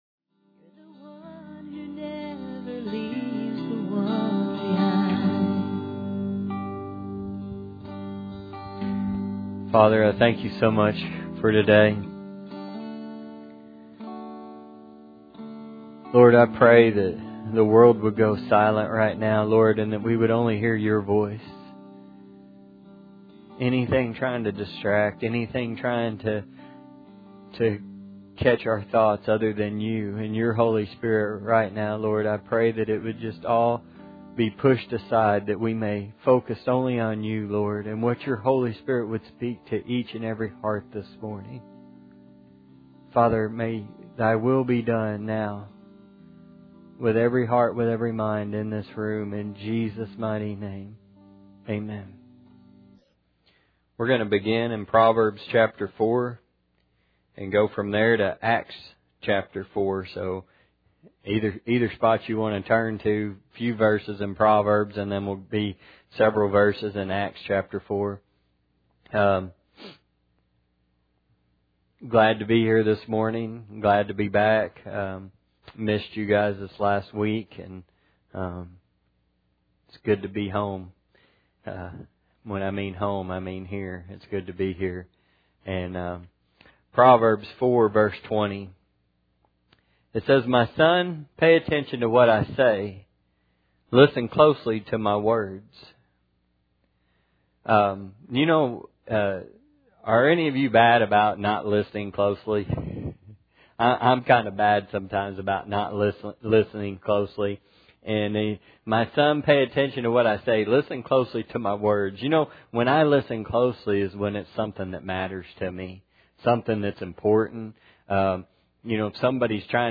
Acts 4 Service Type: Sunday Morning Bible Text